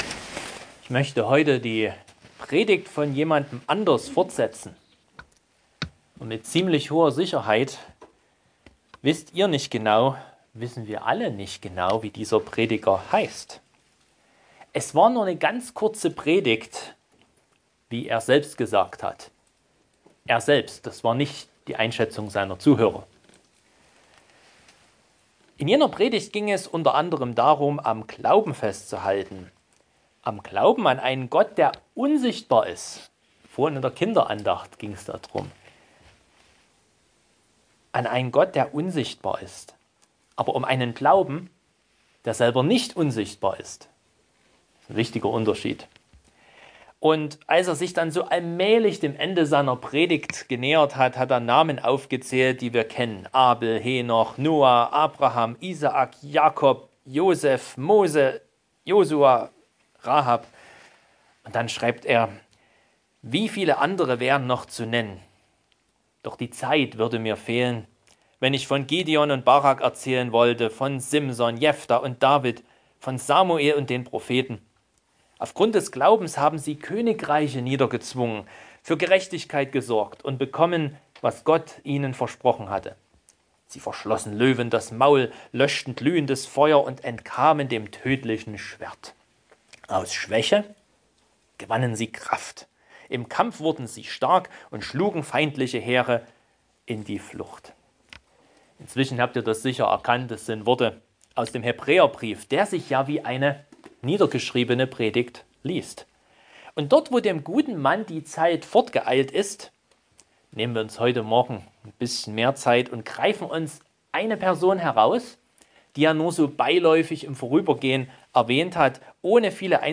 PredigtGideon.mp3